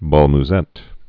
(bäl m-zĕt)